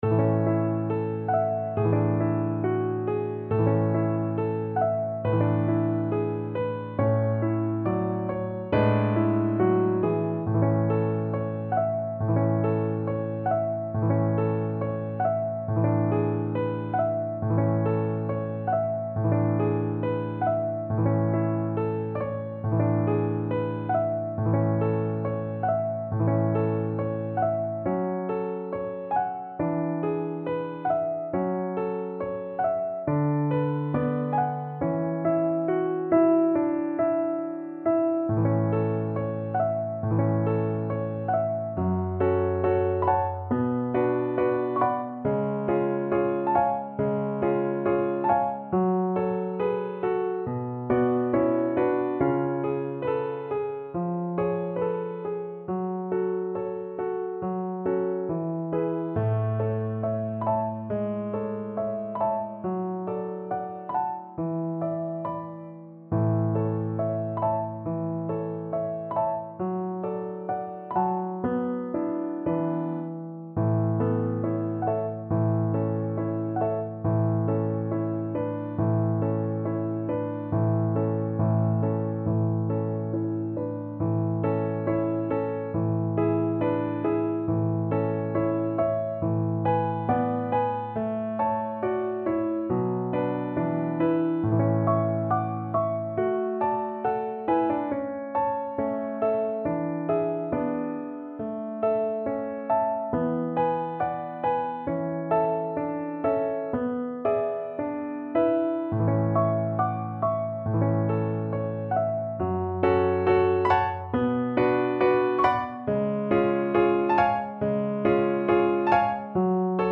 Play (or use space bar on your keyboard) Pause Music Playalong - Piano Accompaniment Playalong Band Accompaniment not yet available reset tempo print settings full screen
A major (Sounding Pitch) (View more A major Music for Violin )
~ = 69 Andante tranquillo